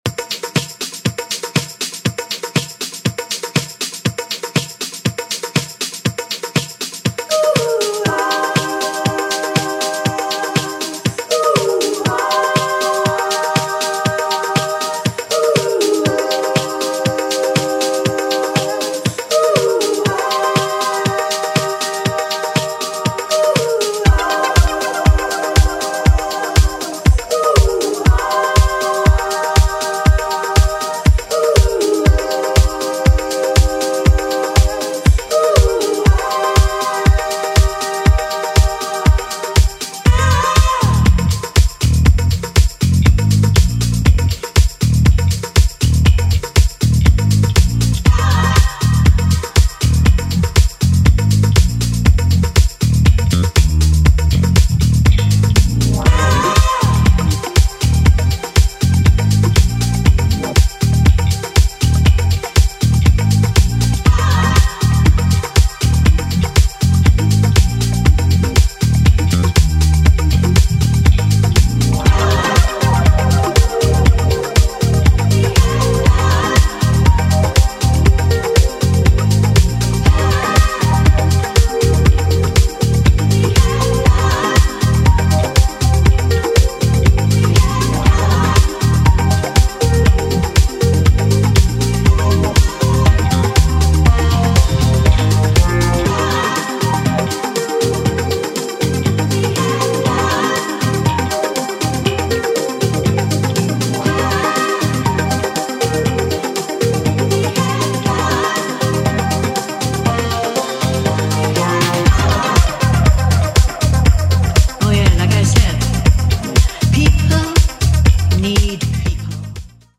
and a sunshine-ready disco dub.